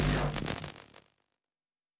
pgs/Assets/Audio/Sci-Fi Sounds/Weapons/Weapon 05 Stop (Laser).wav at master
Weapon 05 Stop (Laser).wav